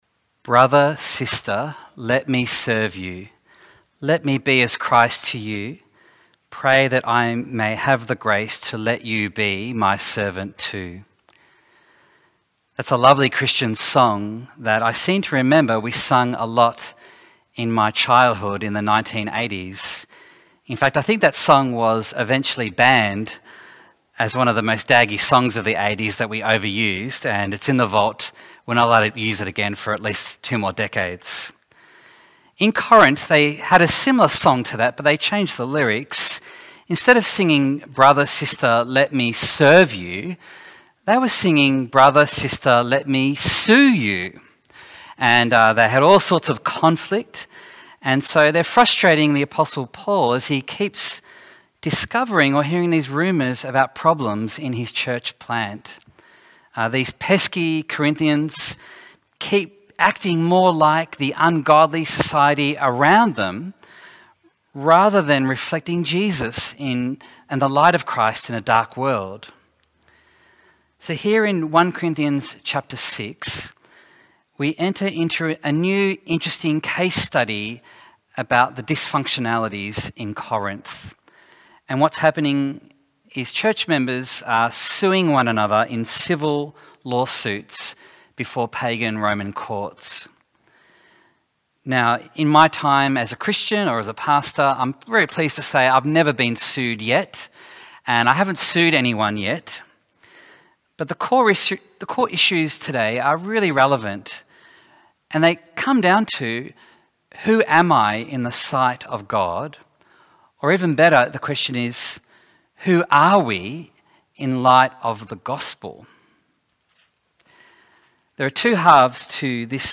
Bible Text: 1 Corinthians 6:1-11 | Preacher